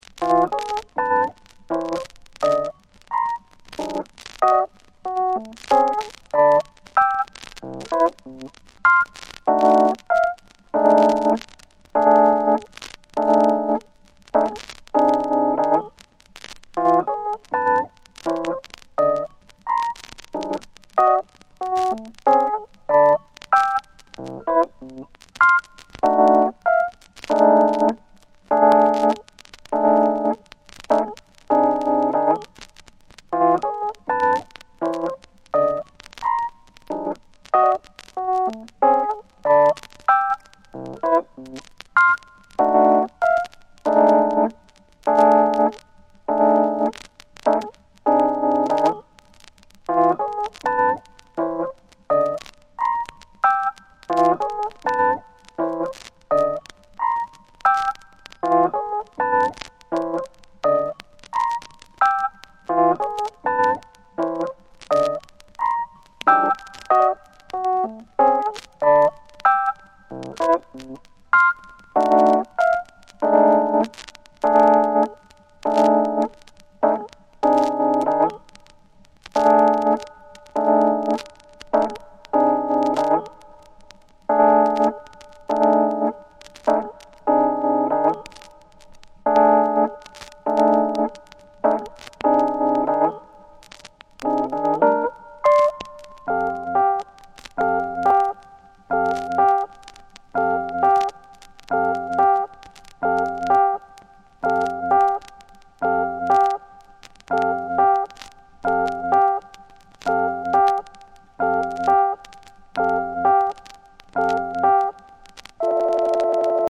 極めて前衛的でエクスペリメンタルな5トラック。